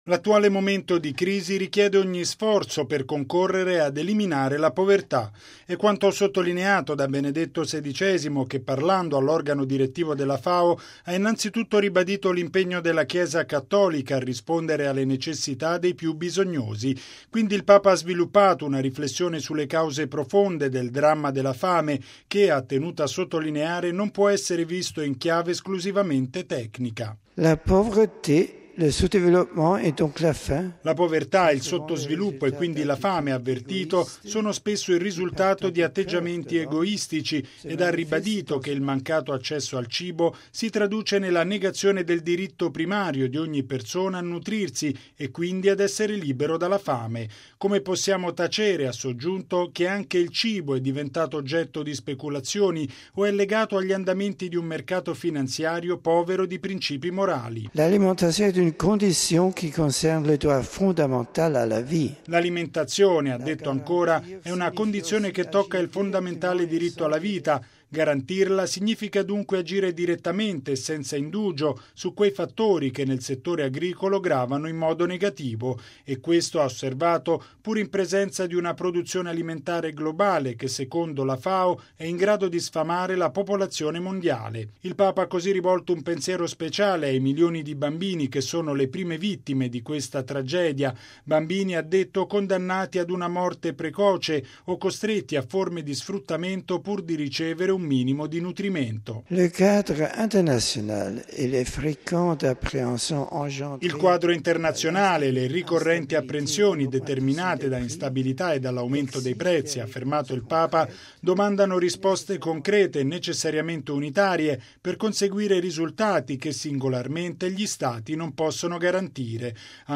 “La pauvreté, le sous-développement et donc la faim sont…